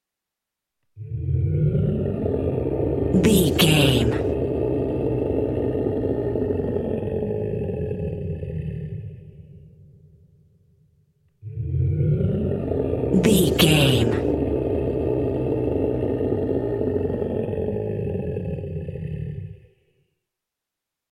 Dinosaur angry scream big creature with without rvrb
Sound Effects
scary
ominous
angry